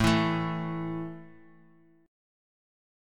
A5 chord